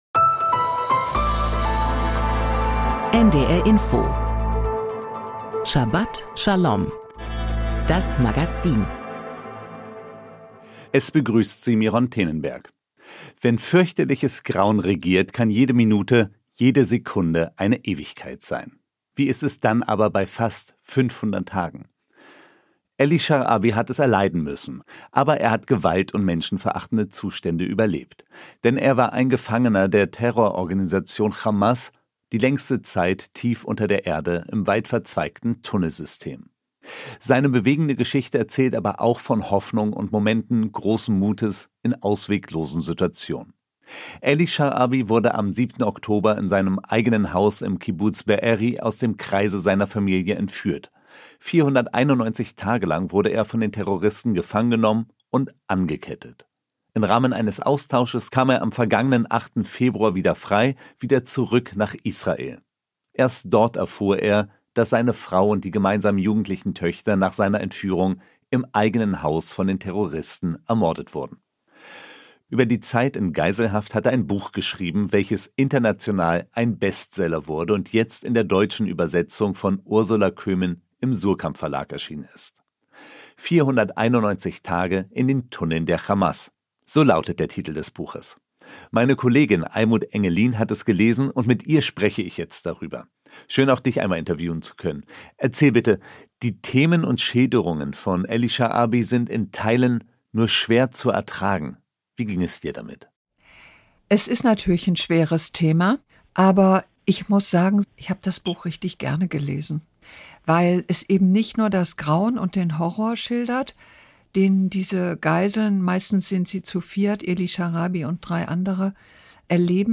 Kollegengespräch
Thora-Auslegung